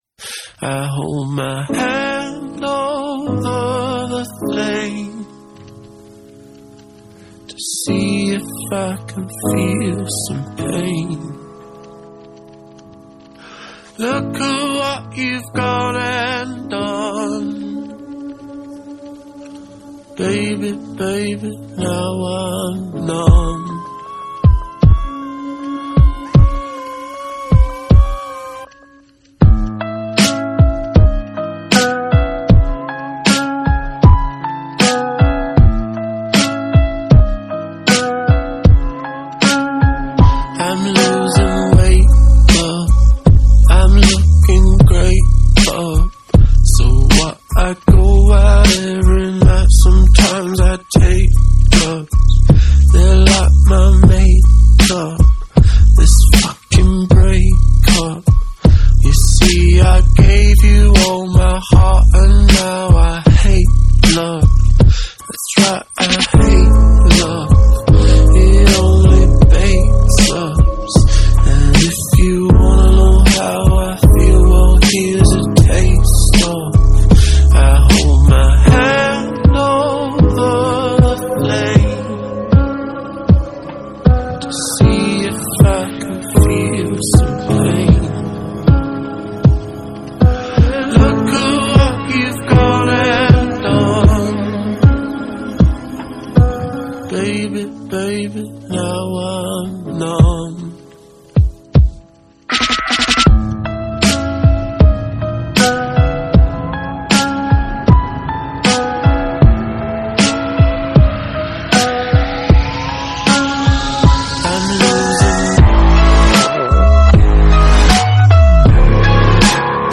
آلبوم پاپ راک احساسی
Pop Rock, Electropop